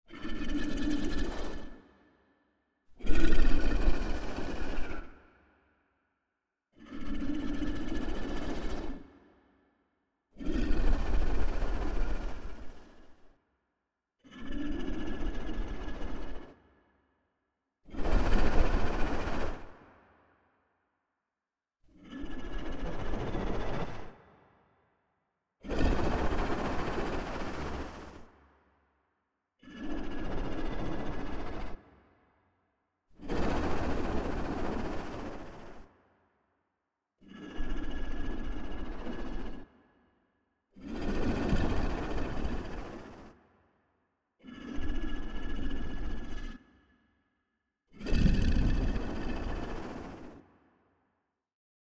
sleeping-dragon-loop-msg.ogg